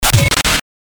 FX-1841-BREAKER